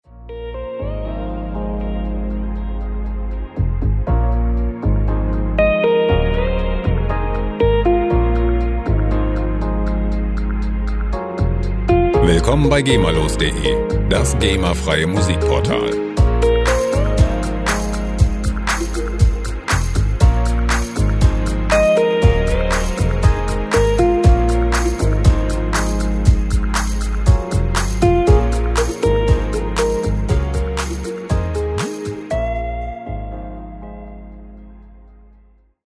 • Balearic Chillout